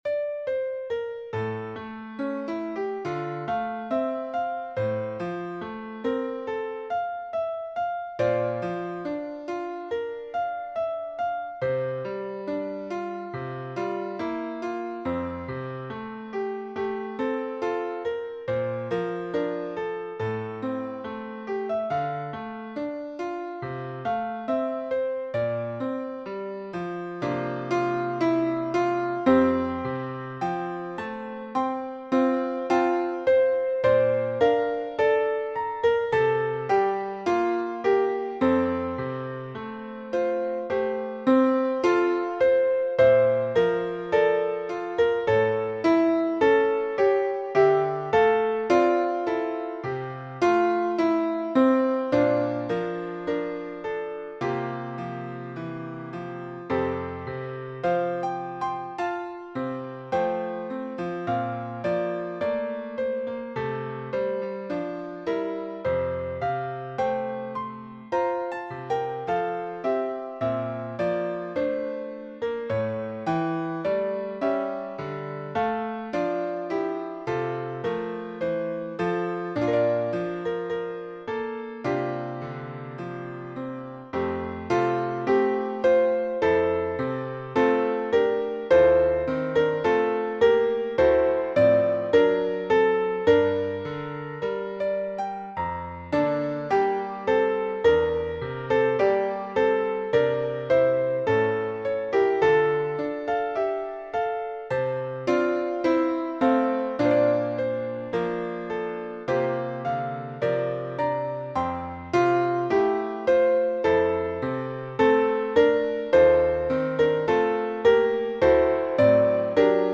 Voicing/Instrumentation: Duet , Piano Solo , Vocal Solo
Hymn Arrangements